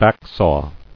[back·saw]